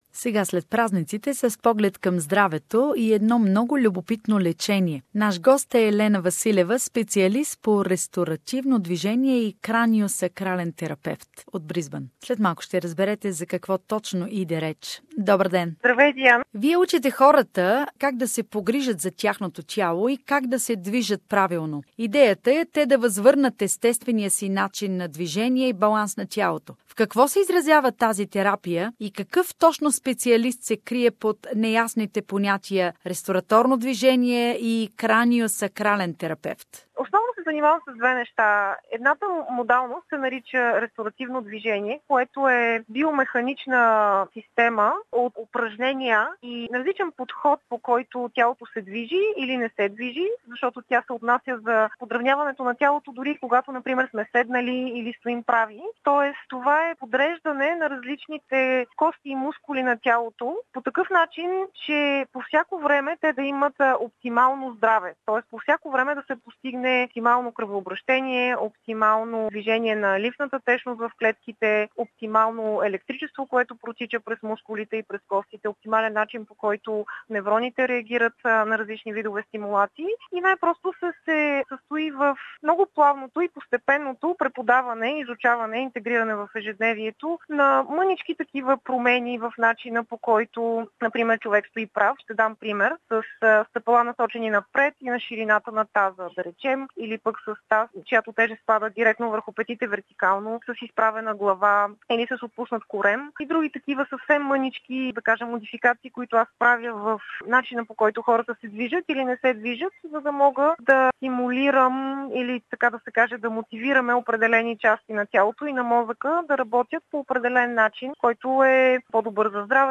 Как да подобрим походката и движенията на тялото си - интервю